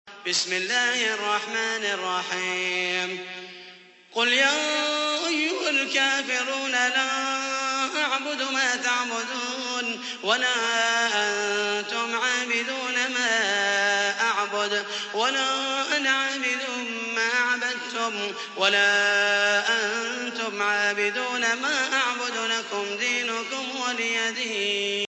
تحميل : 109. سورة الكافرون / القارئ محمد المحيسني / القرآن الكريم / موقع يا حسين